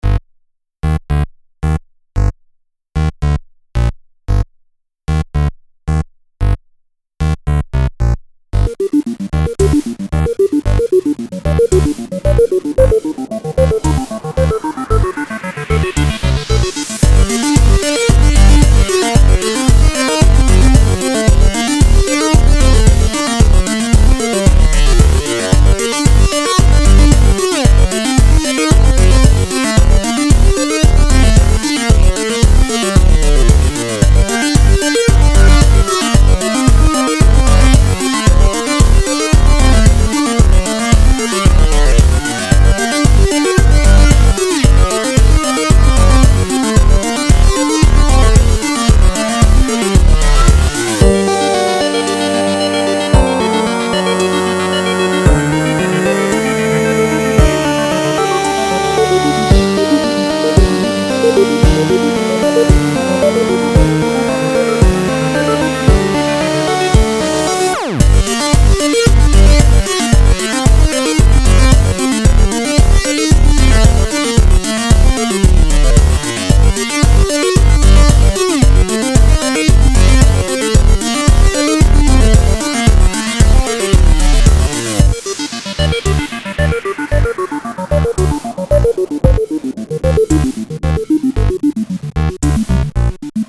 Get ready to get spooked... (boo!)